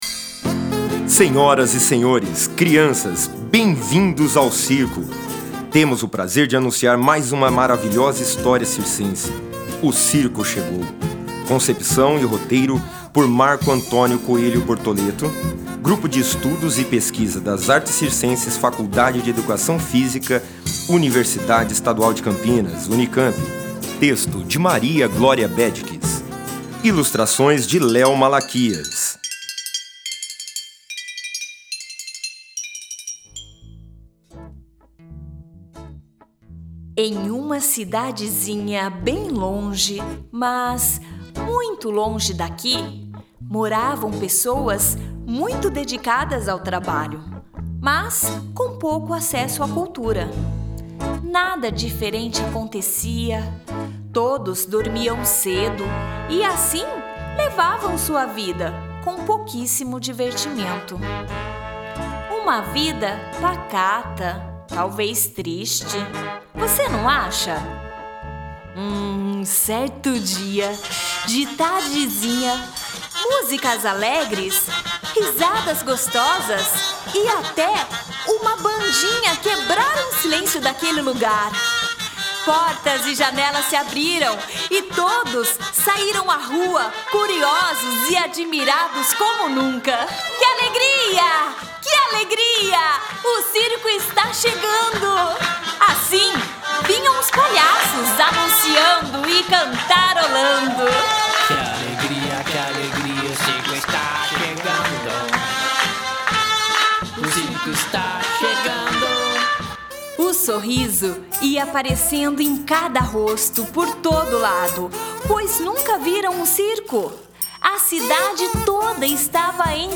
AUDIO-LIVRO / AUDIOBOOK: